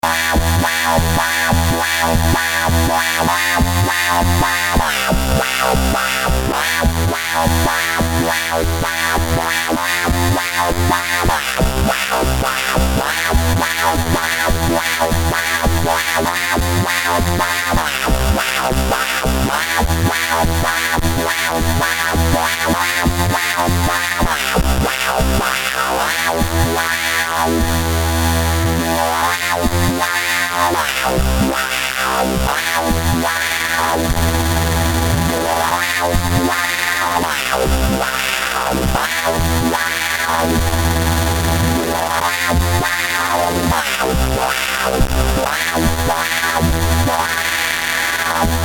2*3 filtered mixed waveforms (pulse/saw/triangle).
The filter is modulated by one LFO, the volume by another LFO. The depth of volume modulation is 0 at the beginning, and changed to +127 at 0:07
The amount of volume modulation is inverted between left and right channel.